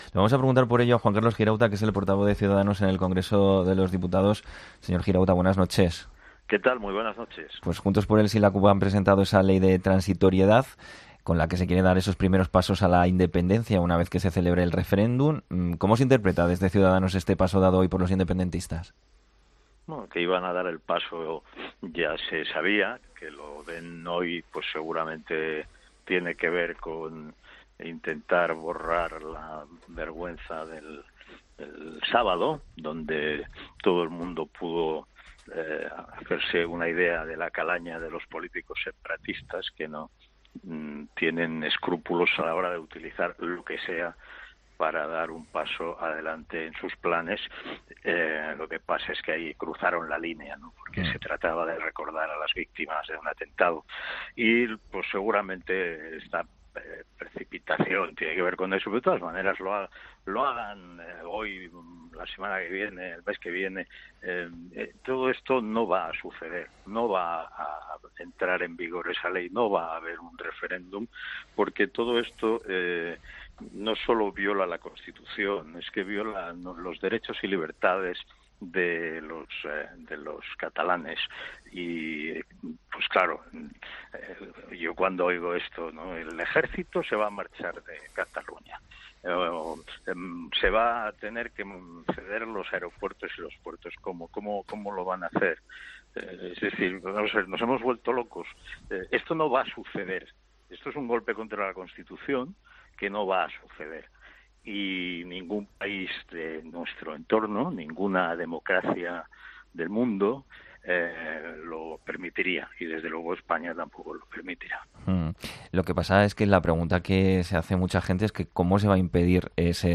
ESCUCHA LA ENTREVISTA COMPLETA | Girauta en 'La Linterna'